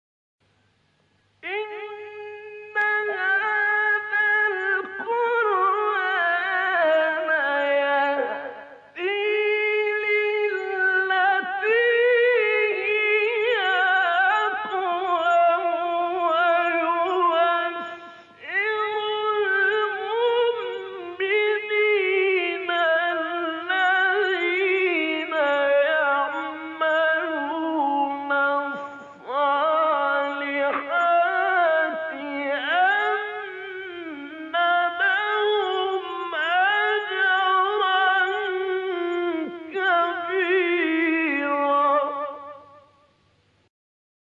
گروه شبکه اجتماعی: 10 مقطع صوتی از قاریان برجسته مصری که در مقام رست اجرا شده‌ است، می‌شنوید.
مقام رست